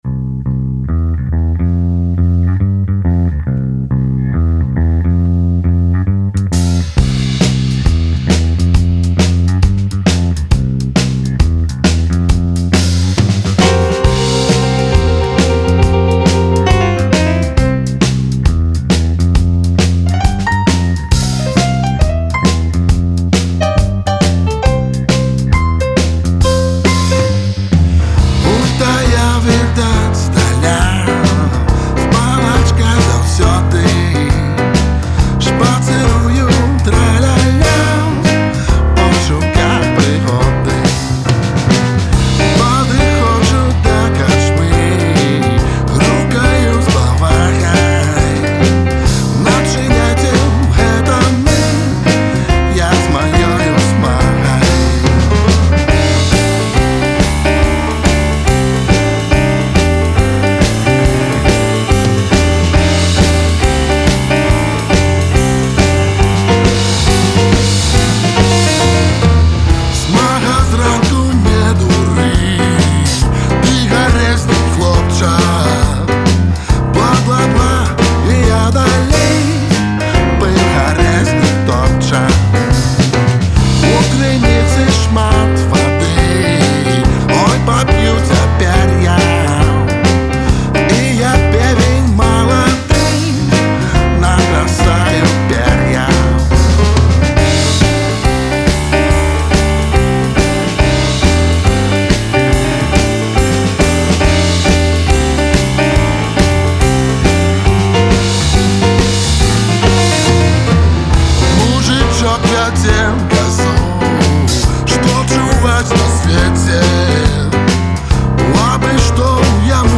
у блюзавай стылістыцы